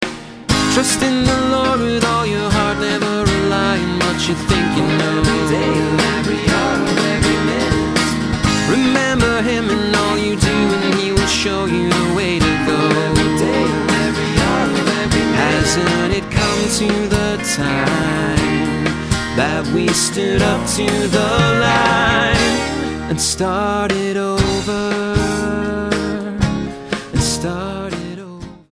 STYLE: Pop
It's all very pleasant but unfortunately not very memorable.